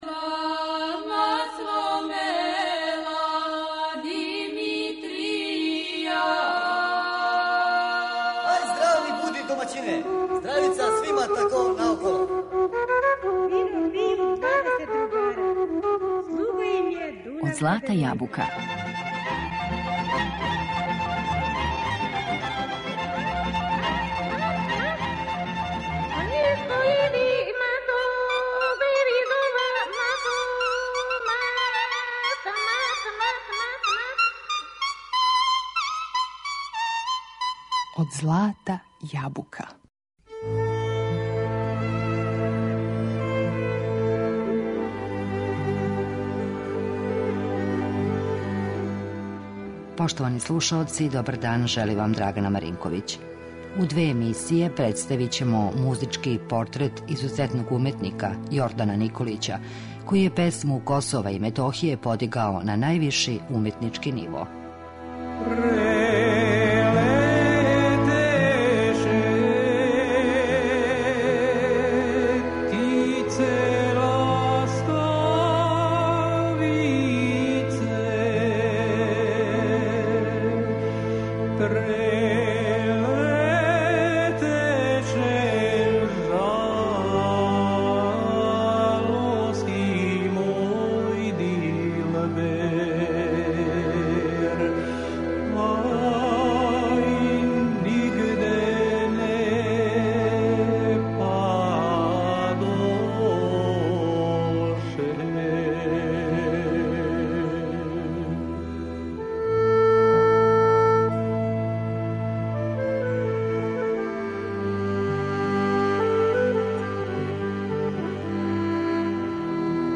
Песме свога краја, поклонио нам је у чистом, изворном стилу, а својом зналачком интерпретацијом, успео је да их оживи.